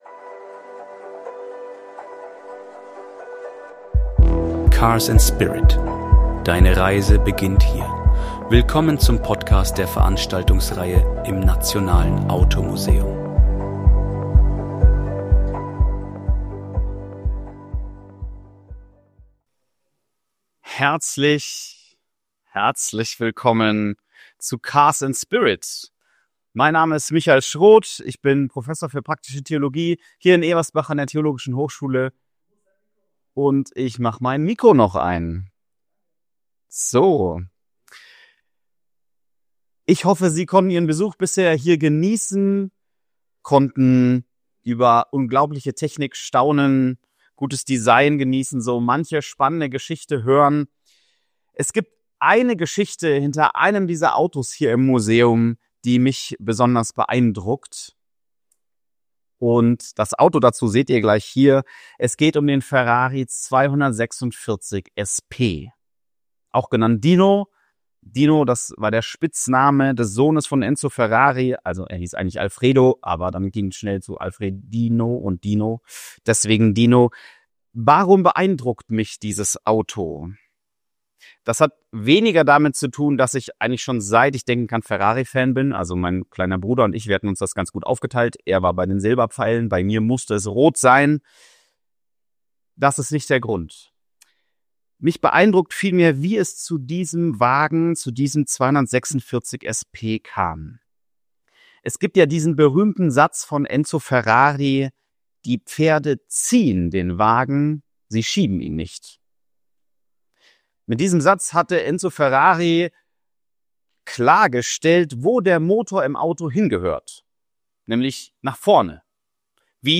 Doch es geht um mehr als Technik: Der Vortrag verbindet den Mut zur Veränderung in der Automobilgeschichte mit der tiefen Sehnsucht nach einem Neuanfang im eigenen Leben.